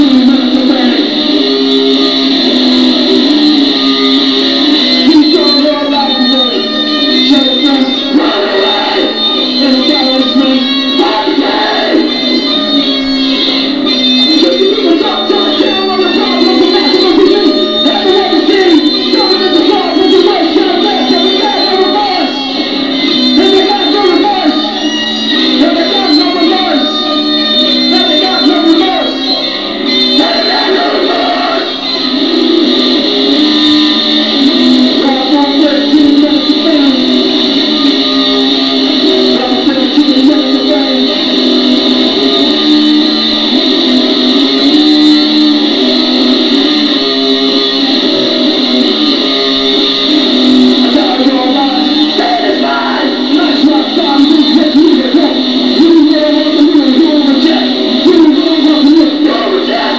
Garage bands at their best! Time to mellow out!